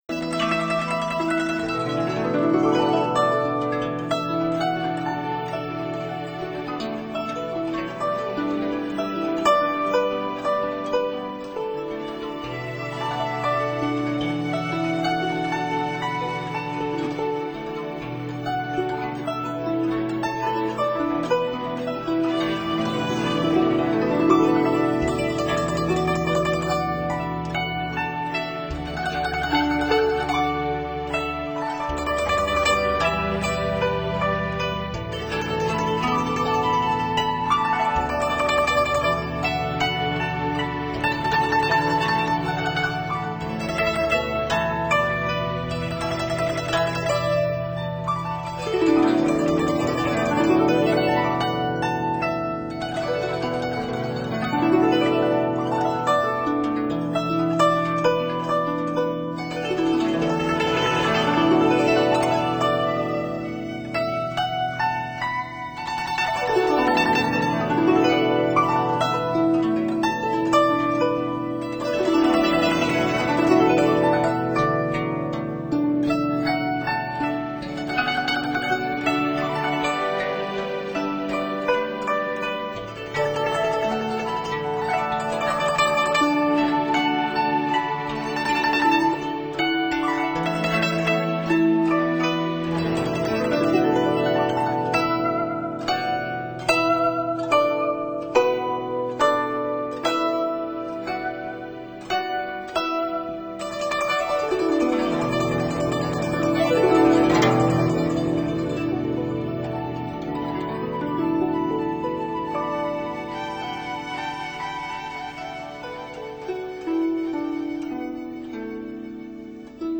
一阙阙筝声，轻轻地流淌你心中， 像飘落到深谷中去的，幽微铃声，
古筝弹奏